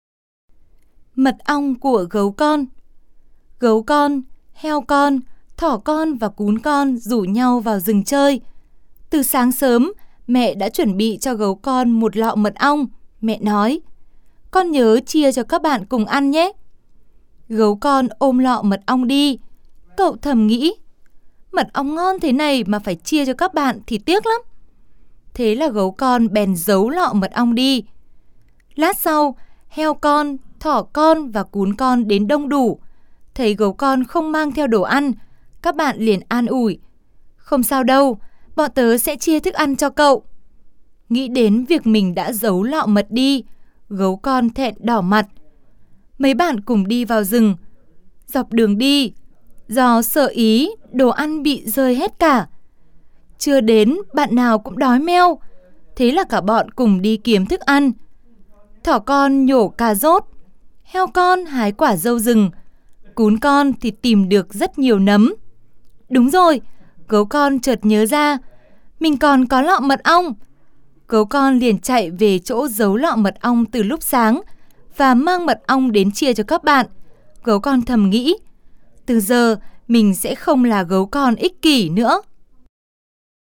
Sách nói | Mật ong của gấu con